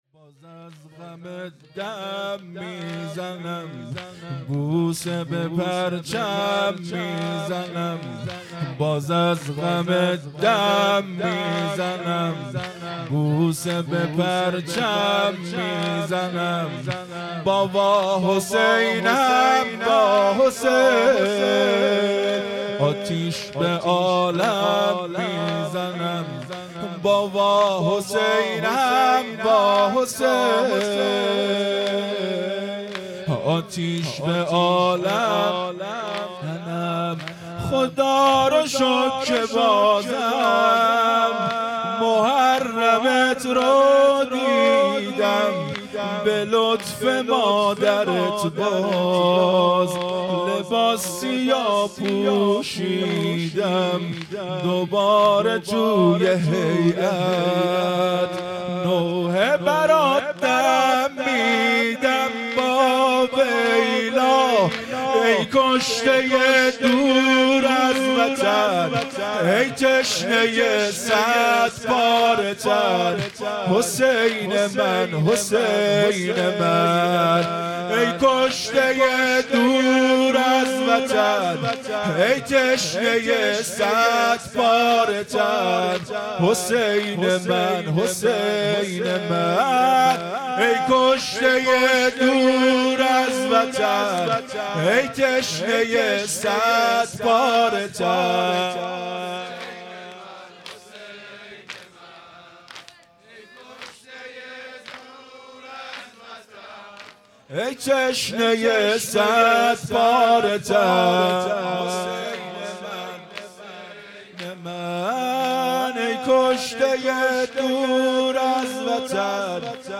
زمینه | باز از غمت دم میزنم مداح
محرم ۱۴۴۵_شب اول